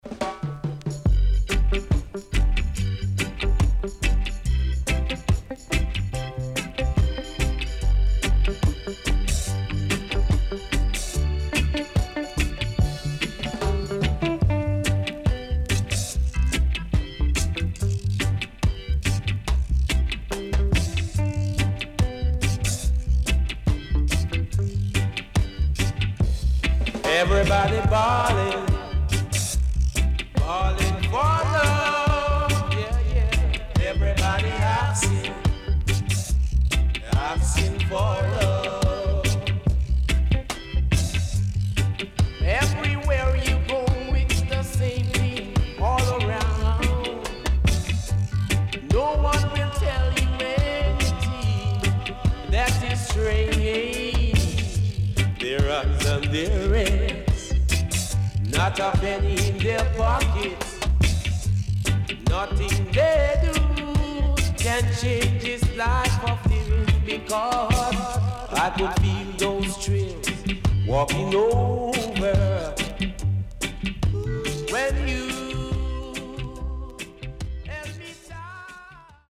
HOME > DISCO45 [VINTAGE]  >  70’s DEEJAY
CONDITION SIDE A:VG(OK)
SIDE A:所々チリノイズがあり、少しプチノイズ入ります。